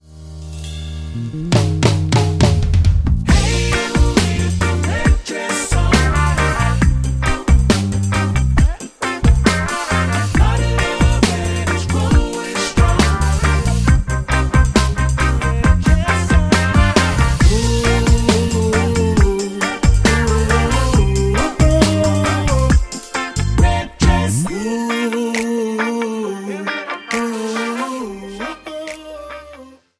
Bb
Backing track Karaoke
Pop, 2010s